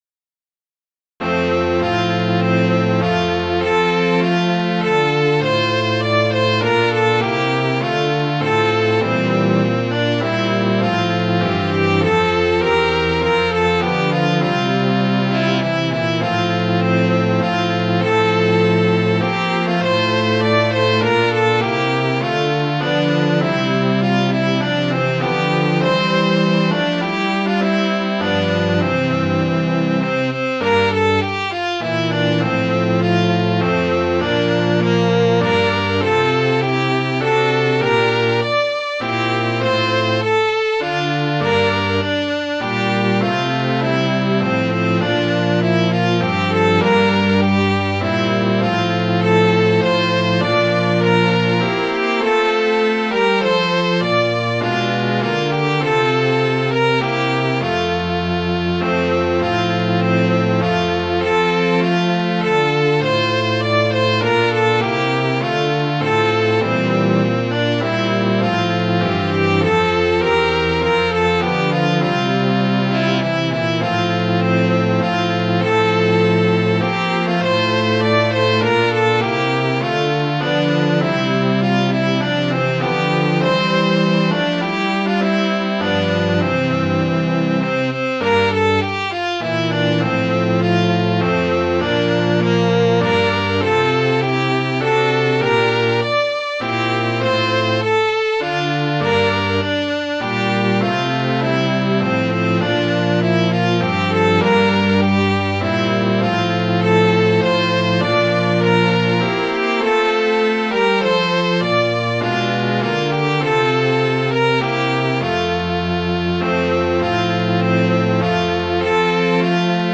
Midi File, Lyrics and Information to Will Watch